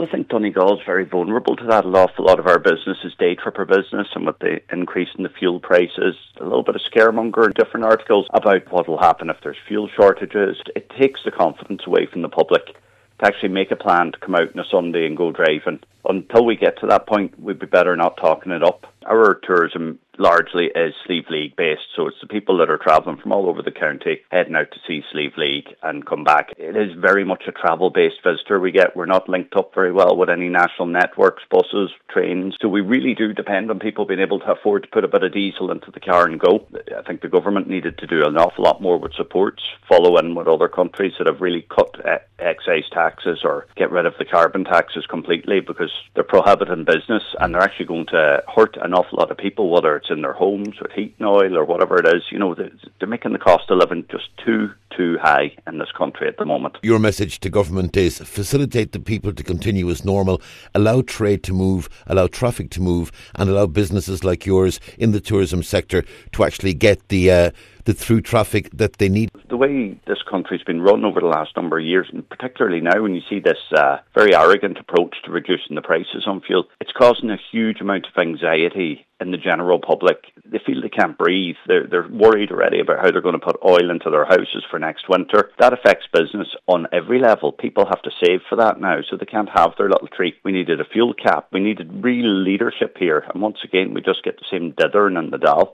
speaking to Highland Radio News